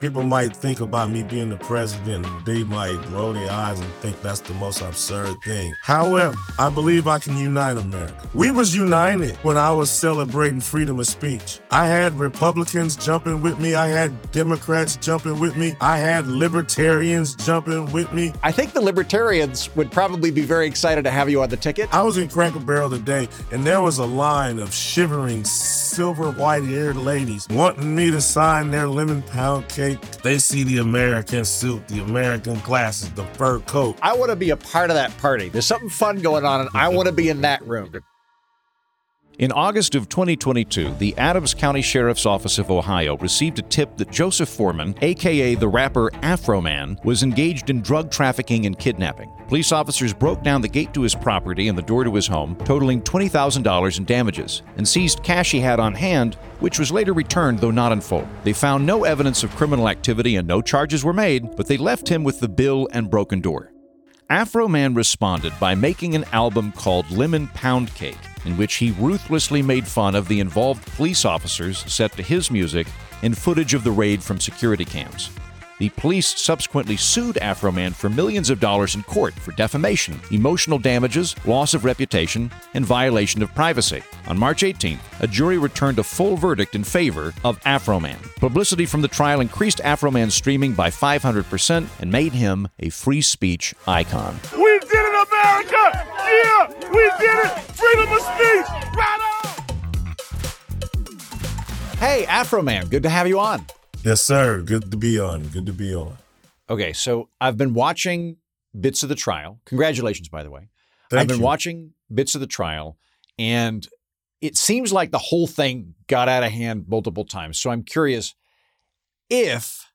Rapper Afroman discusses his free speech court victory, why he thinks he could unite America, and whether he feels pressure to always be high.